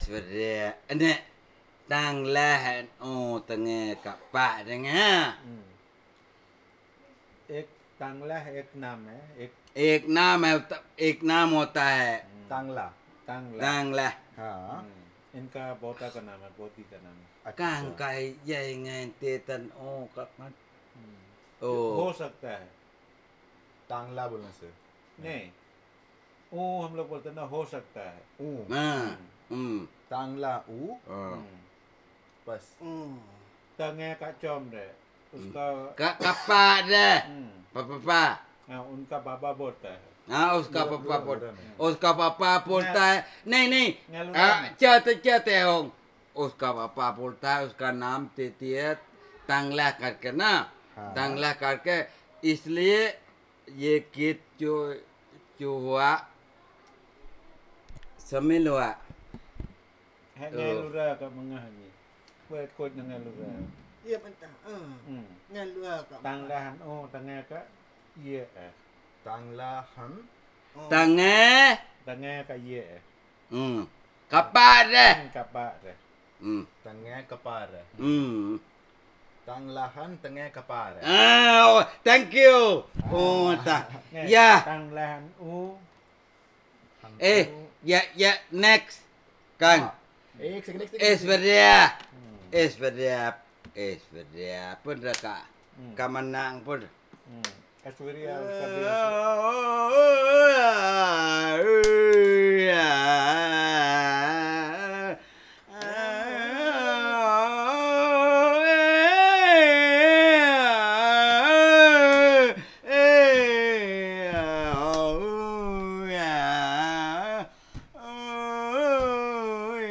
Lullaby in Luro